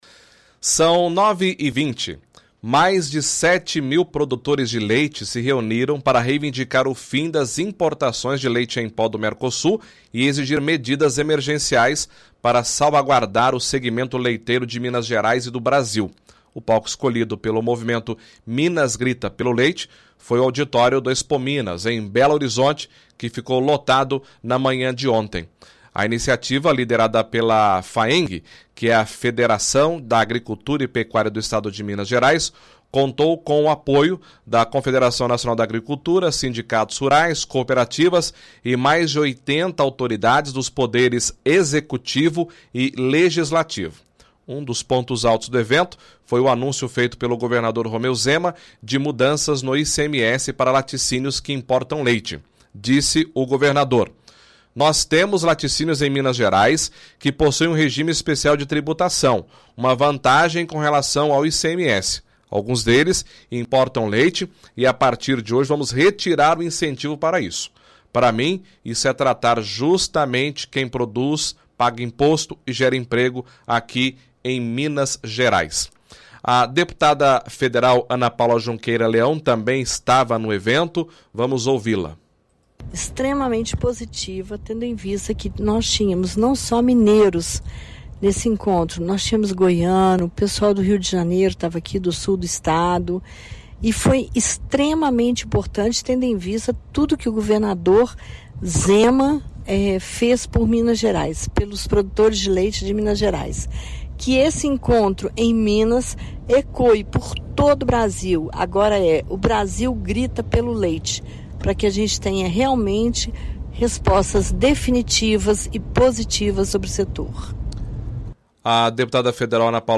– Entrevista deputada federal, Ana Paula Leão, fala que foi extremamente positivo o evento, tínhamos produtores de vários estados e foi extremamente importante o apoio do governador Zema.